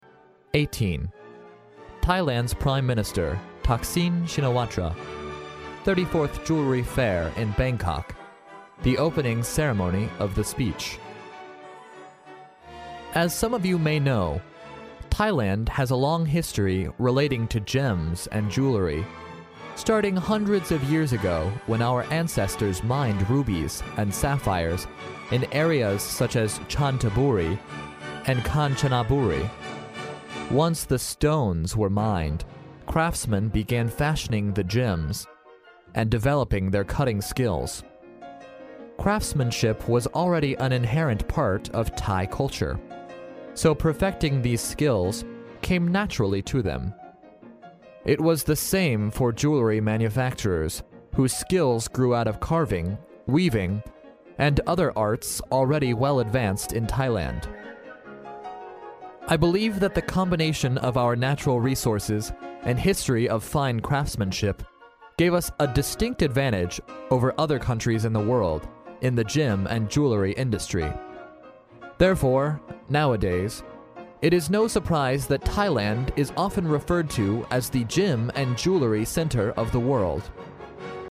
历史英雄名人演讲 第25期:泰国总理他信在第34届曼谷珠宝交易会开幕式上的演讲(1) 听力文件下载—在线英语听力室